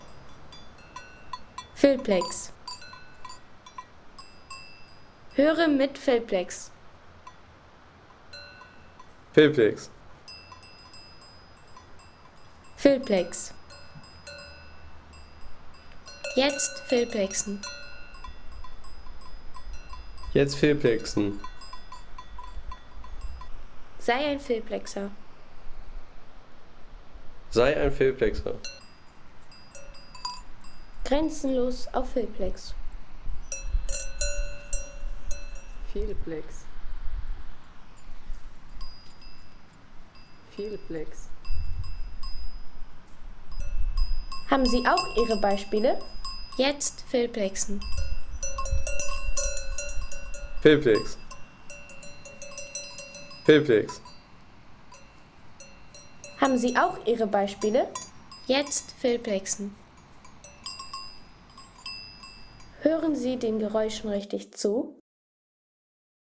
Kuhglocken
Kuhglocken im Formazza-Tal.